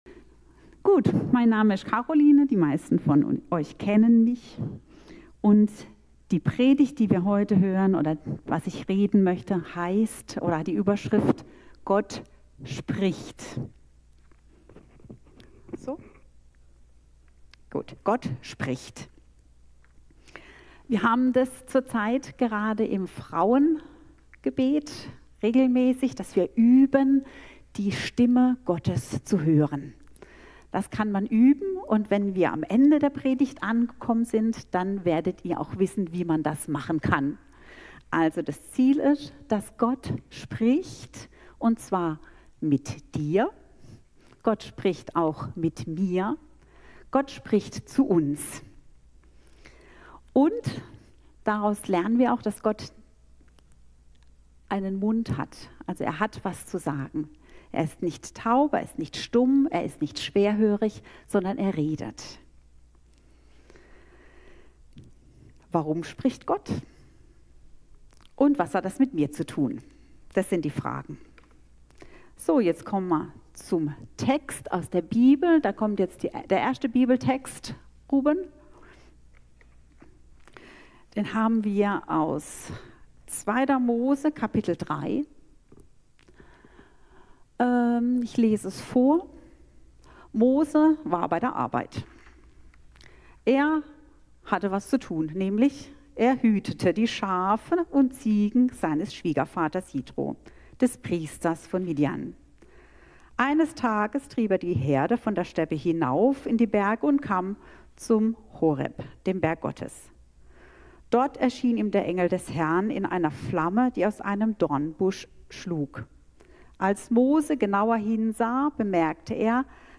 Thema: Gott spricht! Datum: 09.02.2025 Ort: Gospelhouse Kehl